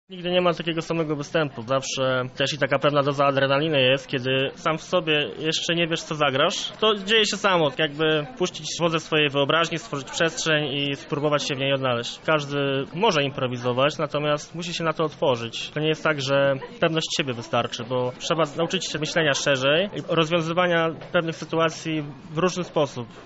jeden z aktorów Odskoczni.